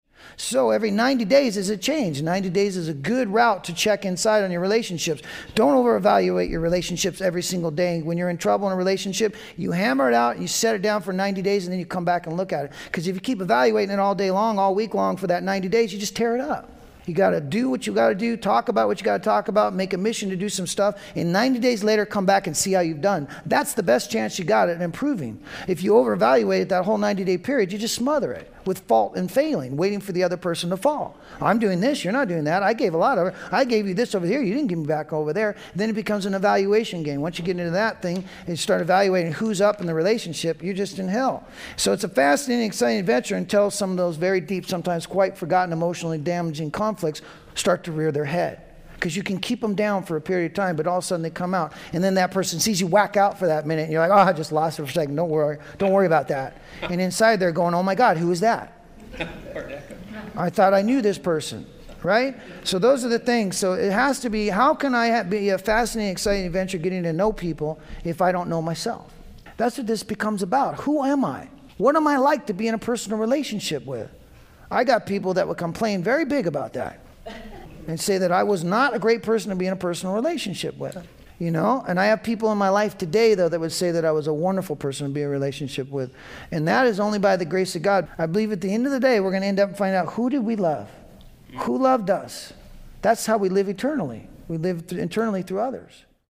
A reflective recovery workshop about staying present through discomfort, understanding emotional patterns, and learning not to walk away from inner growth when things feel hard.\n\n
This audio archive is a compilation of many years of lecturing.